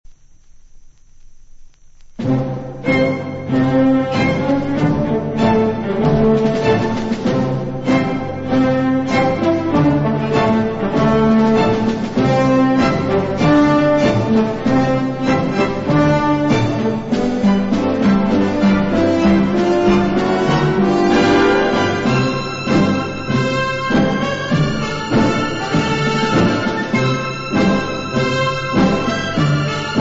• marce militari
• registrazione sonora di musica